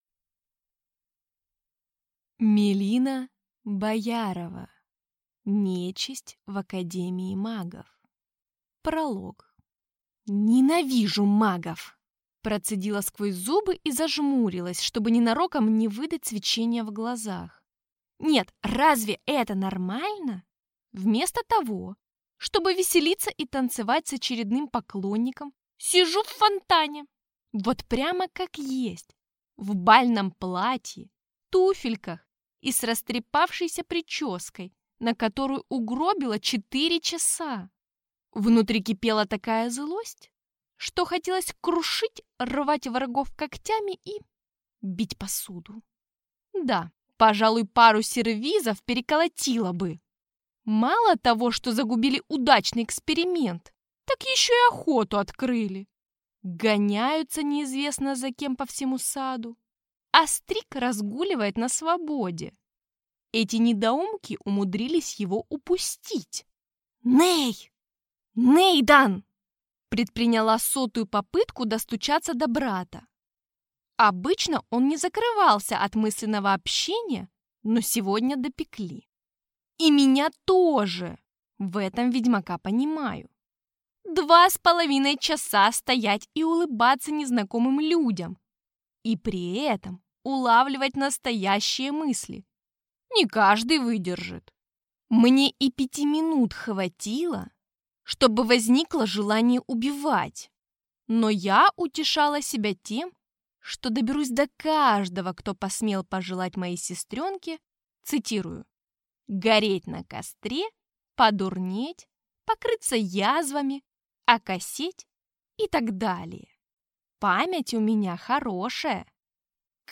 Аудиокнига Нечисть в академии магов | Библиотека аудиокниг